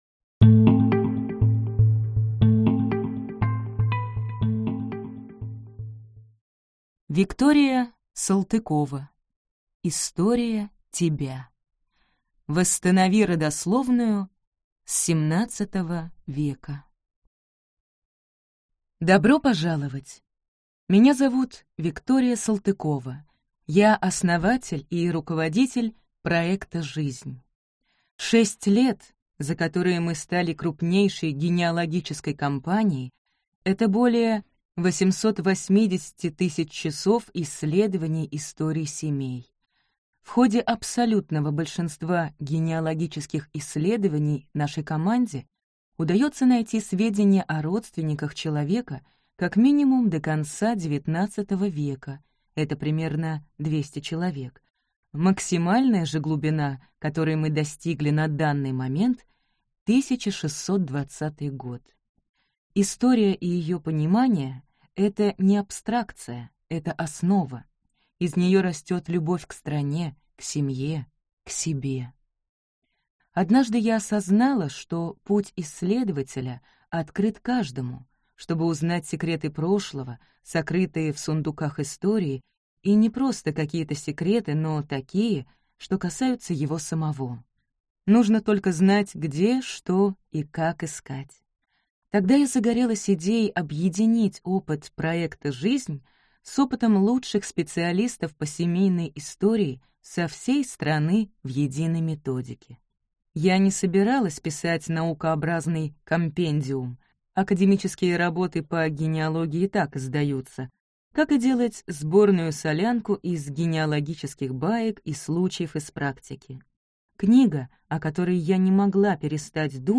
Аудиокнига История тебя. Восстанови родословную с XVII века | Библиотека аудиокниг